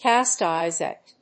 アクセントcást (shéep's) èyes at…